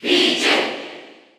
File:Pichu Cheer English PAL SSBU.ogg
Crowd cheers (SSBU) You cannot overwrite this file.
Pichu_Cheer_English_PAL_SSBU.ogg.mp3